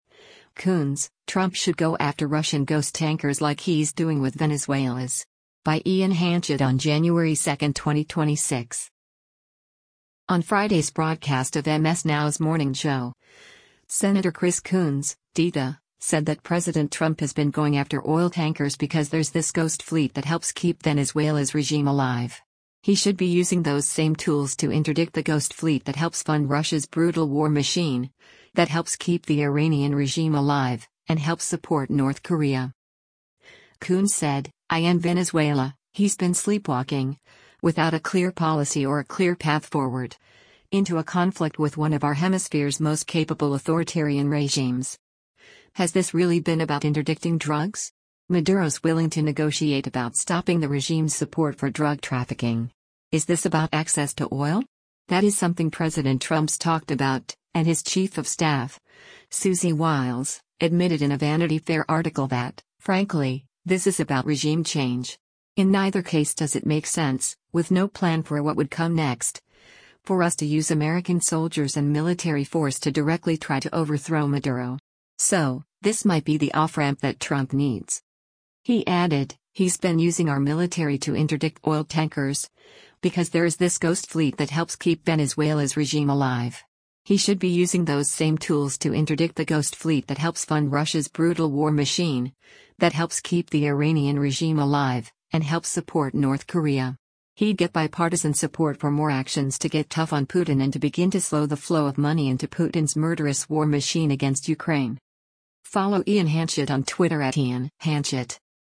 On Friday’s broadcast of MS NOW’s “Morning Joe,” Sen. Chris Coons (D-DE) said that President Trump has been going after oil tankers because there’s “this ghost fleet that helps keep Venezuela’s regime alive.